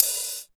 59 OP HAT.wav